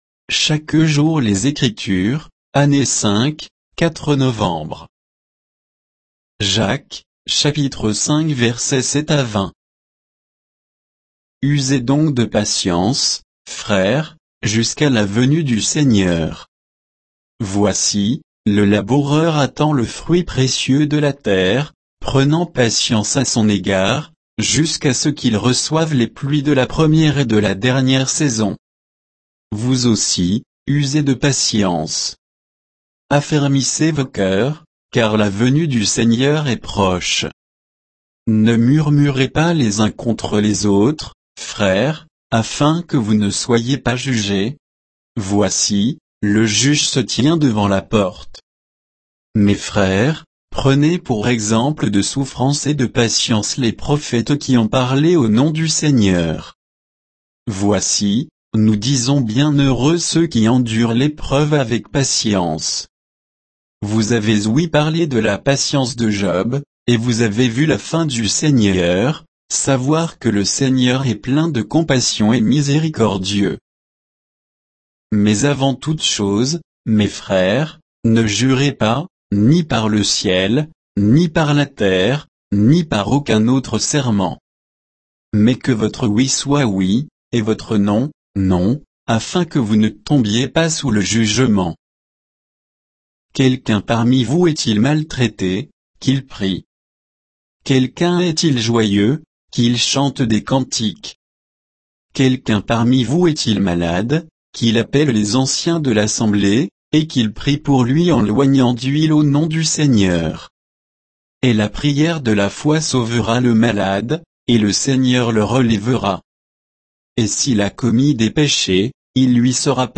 Méditation quoditienne de Chaque jour les Écritures sur Jacques 5, 7 à 20